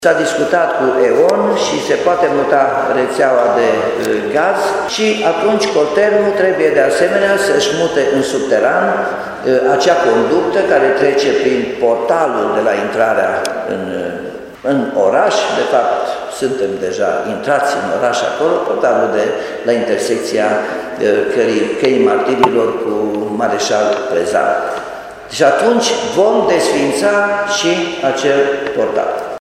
După mai multe încercări eșuate de coborâre a magistralei Colterm care traversează Calea Martirilor, primăria Timișoara s-a înțeles cu Eon pentru mutarea rețelelor de gaz astfel încât conducta de mari dimensiuni să poată fi introdusă în subteran, spune primarul Nicolae Robu.